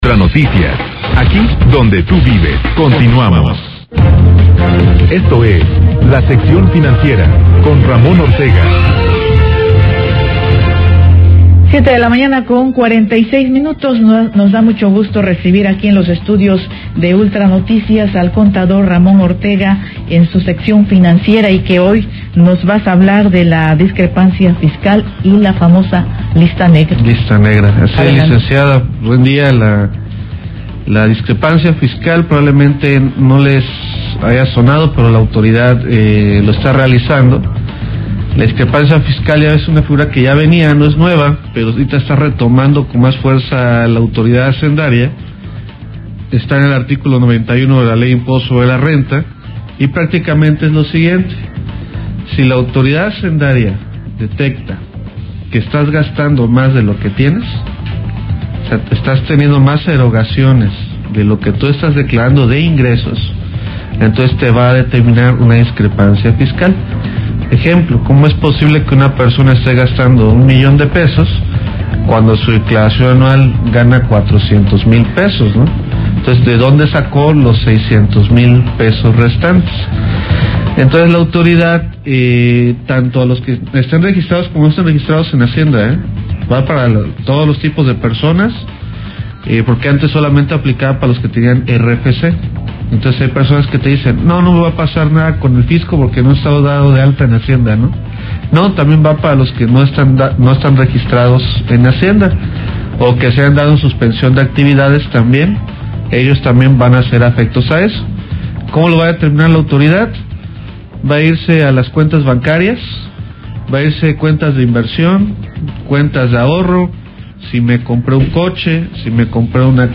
ENTREVISTA ULTRA NOTICIAS – DISCREPANCIA FISCAL Y LA «BLACK LIST»